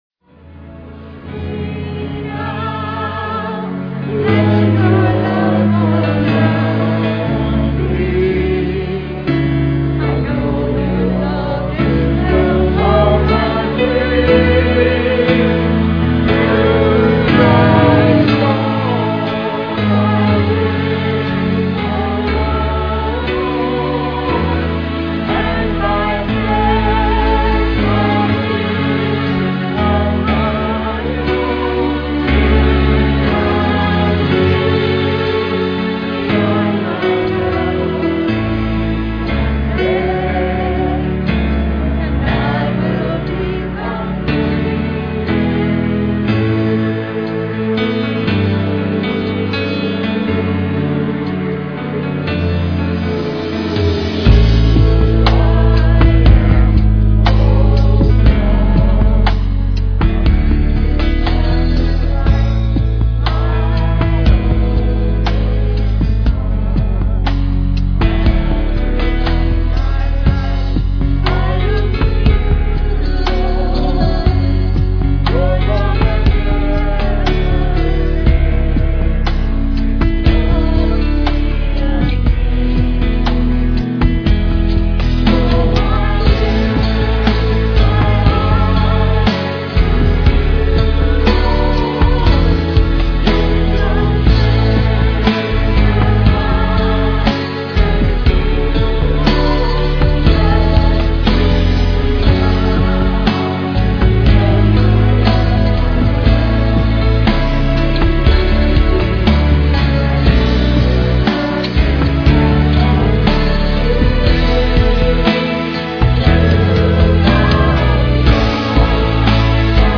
PLAY Man of Miracles, Part 4, Jul 30, 2006 Scripture: Luke 5:4-10. Scripture Reading